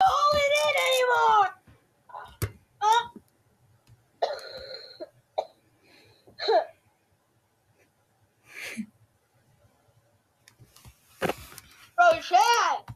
voice record